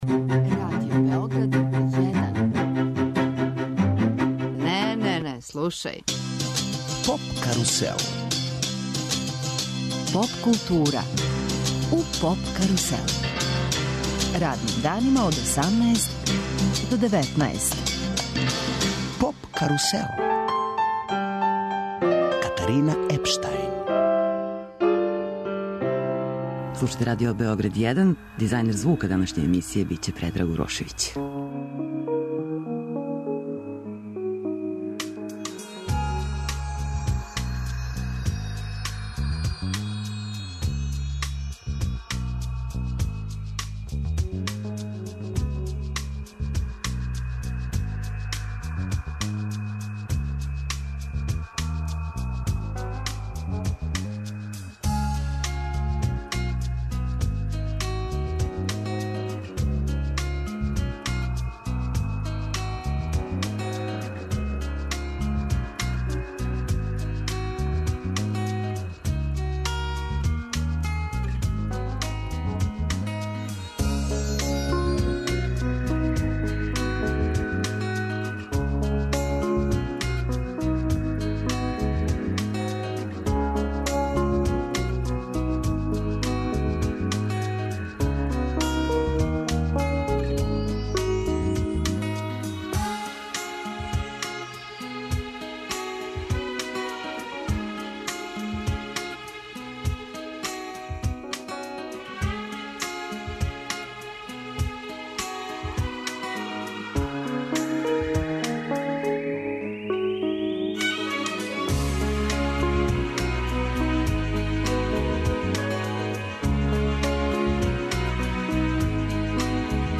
Емитујемо интервју са Кемалом Гекићем, прослављеним пијанистом, који ће одржати концерт у Великој сали Коларчевог народног универзитета, 27. априла.